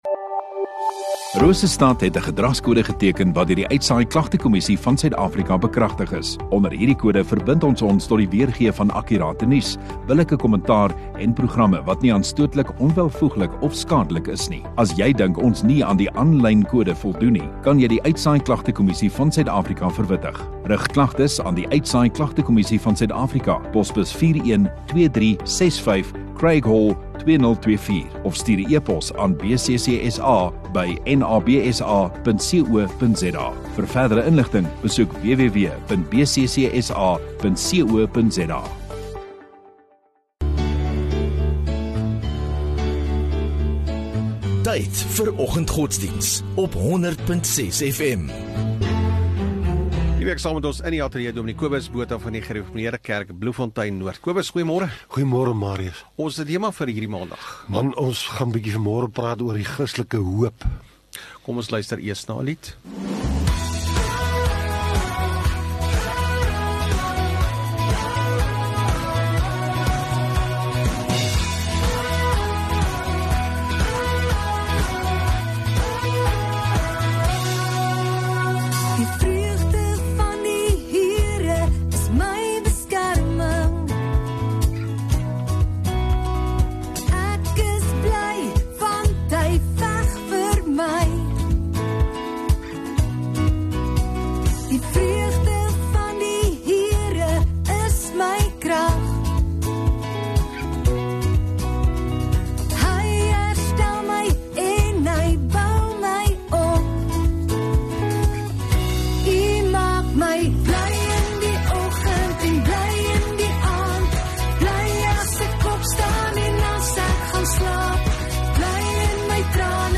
30 Sep Maandag oggenddiens